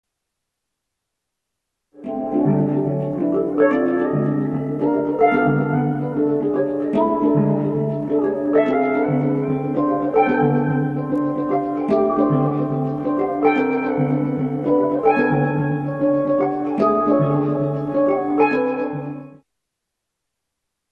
Pickup TB68/1P y el procesamiento digital del hang
Un hang con pastilla nos permite ampliar, y mucho, los límites que marca la propia estructura del instrumento, tal como escuchamos en las siguientes muestras de sonido.
Muestra III. Proceso digital en el hang.
hang_registro_3.mp3